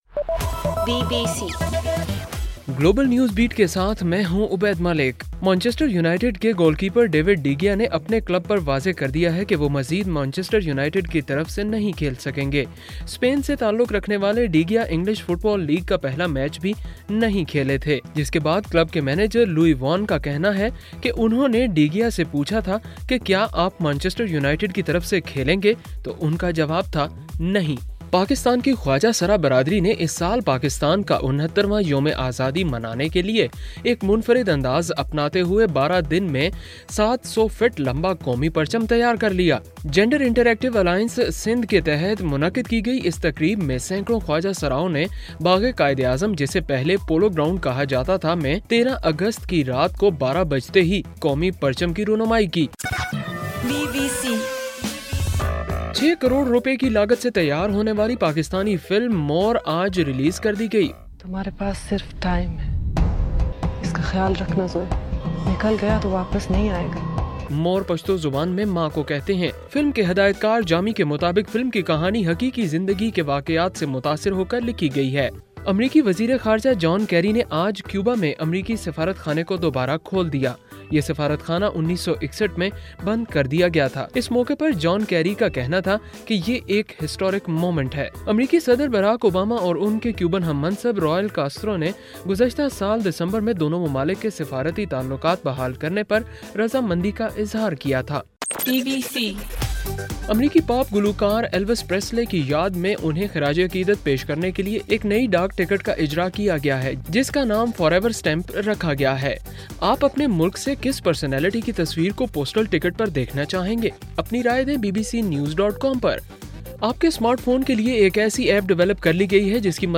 اگست 14: رات 12 بجے کا گلوبل نیوز بیٹ بُلیٹن